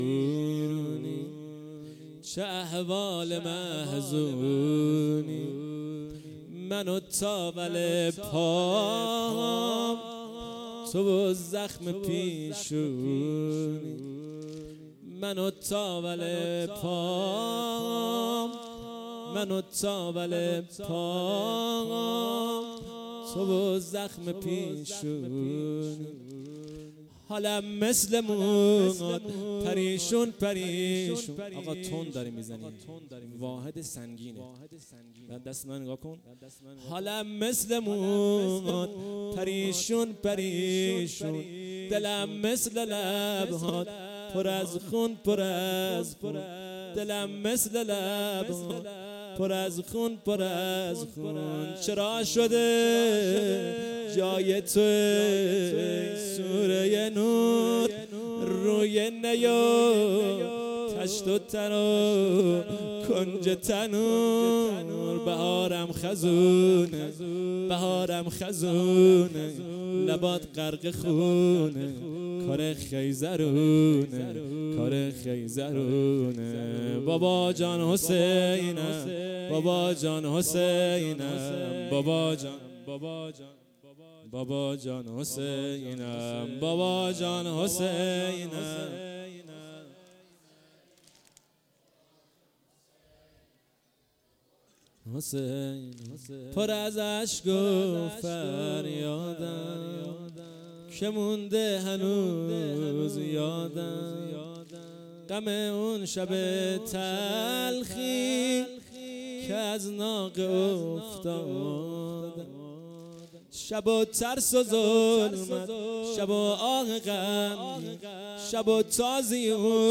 سینه زنی واحد شب سوم محرم98
استان چهارمحال وبختیاری روستای شیخ شبان